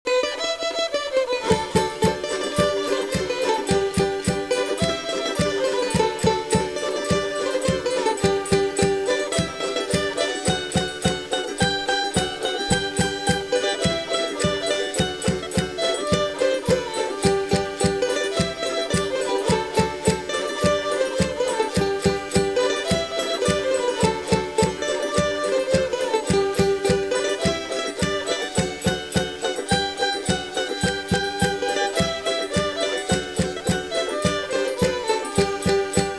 FOLKMUSIC